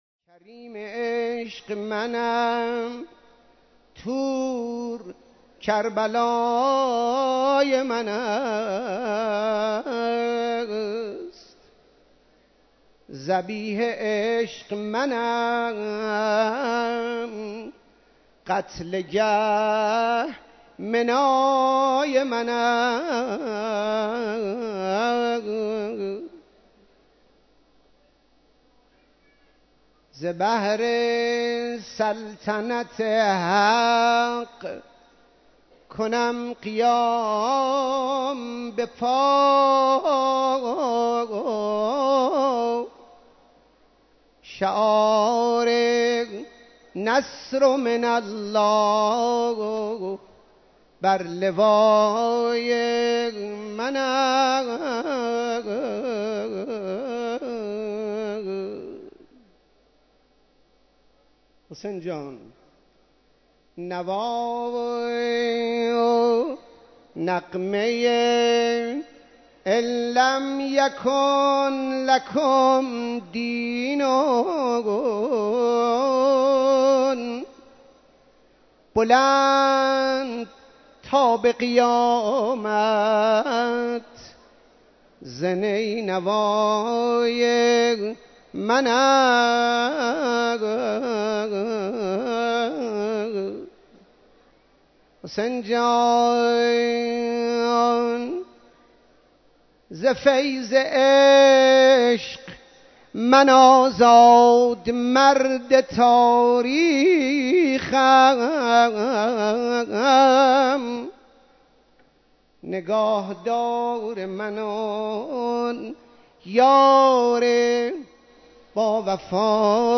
مراسم عزاداری شام غریبان حضرت اباعبدالله الحسین علیه‌السلام
مداحی